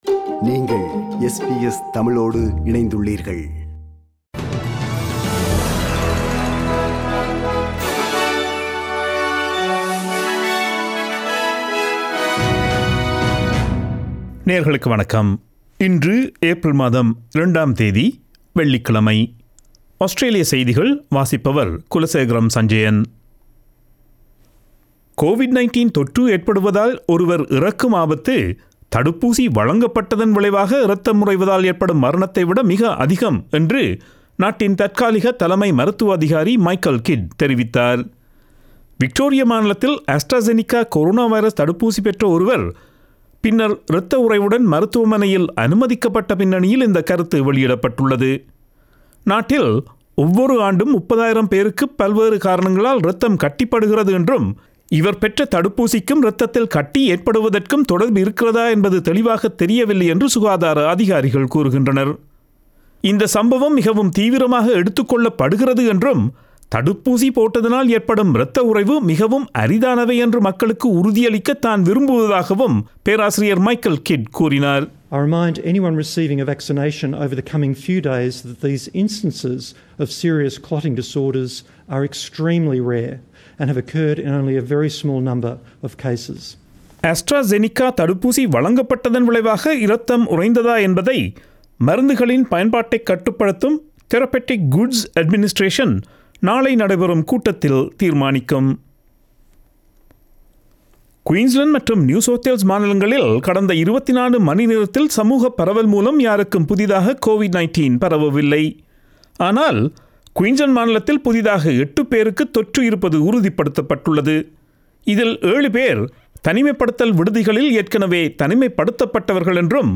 Australian news bulletin for Friday 02 April 2021.